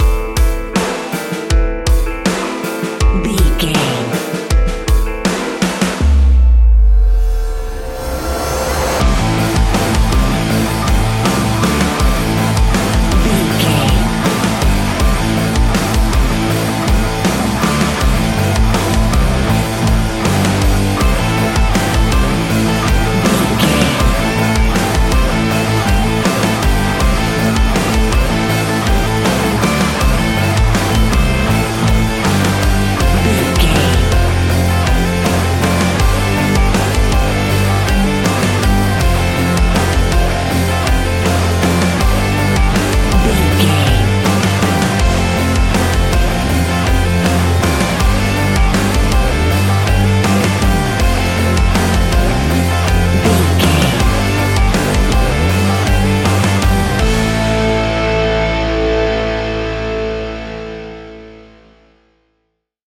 Ionian/Major
A♭
hard rock
heavy metal
instrumentals